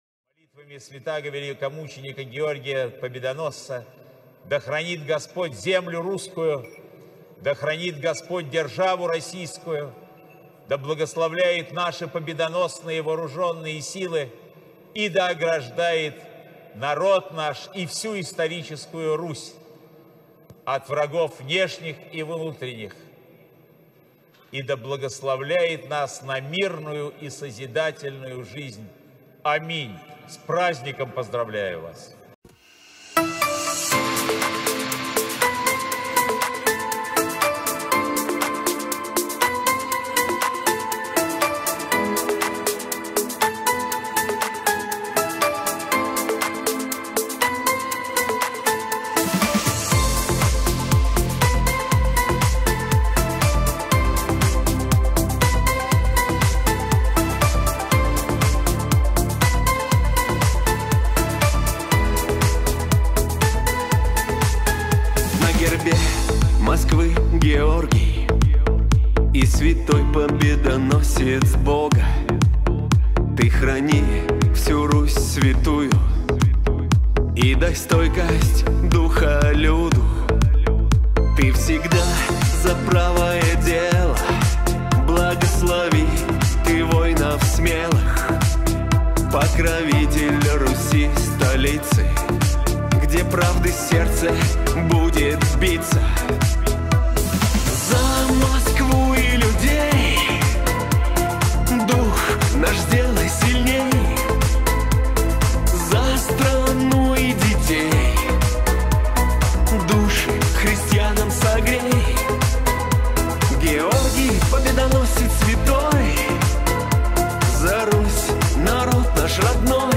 Скачать музыку / Музон / Музыка Шансон